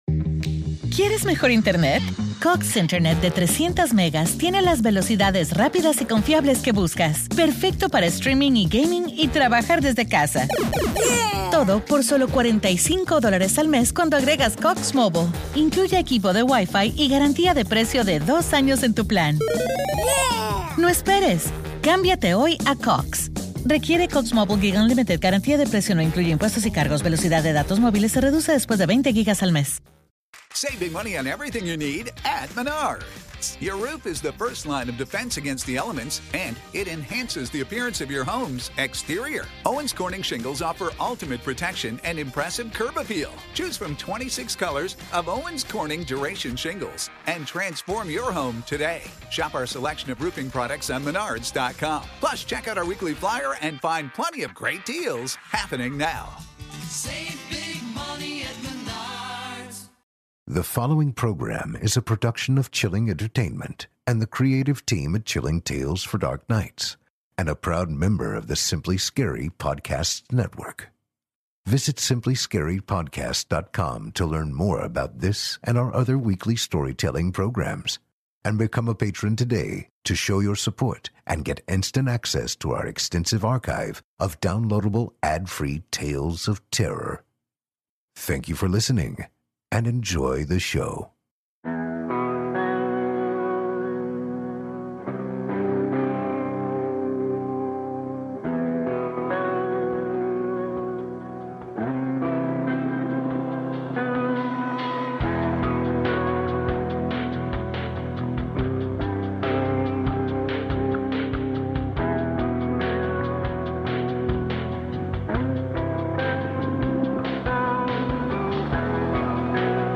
Tonight we’ll be reading two stories, exploring both inner and outer space.